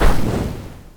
spell-impact-1.mp3